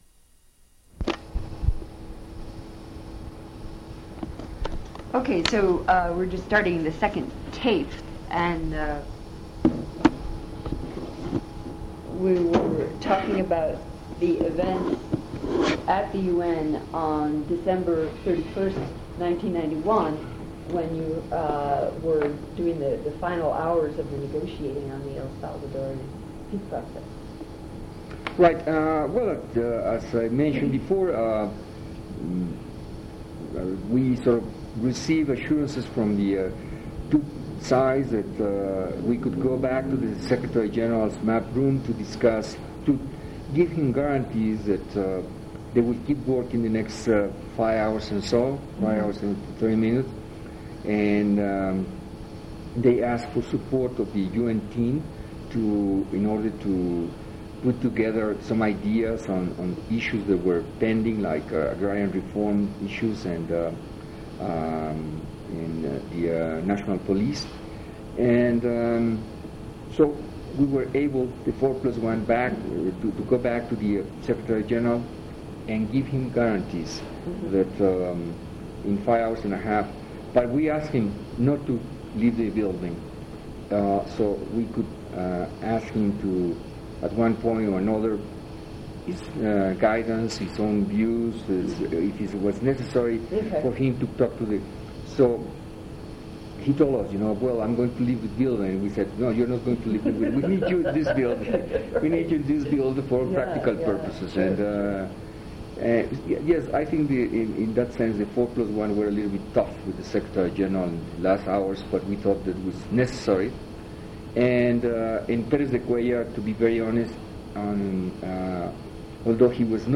Interview with Jorge Montaño / - United Nations Digital Library System